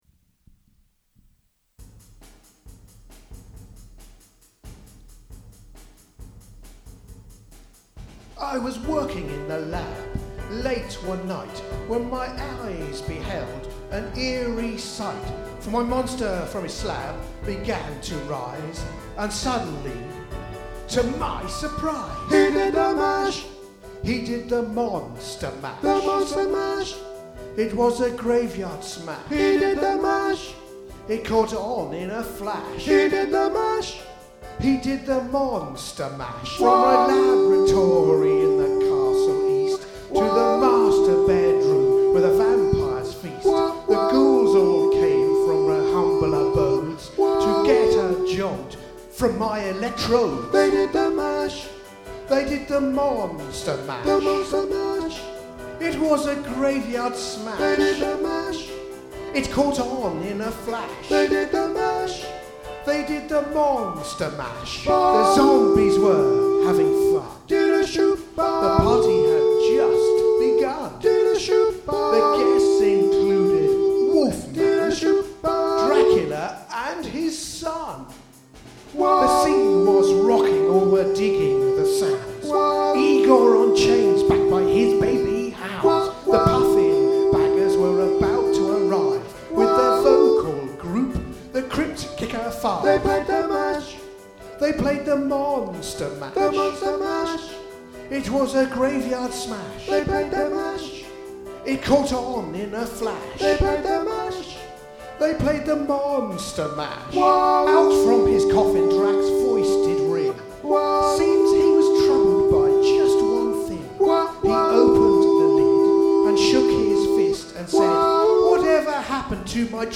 Monster Mash All Voices | Ipswich Hospital Community Choir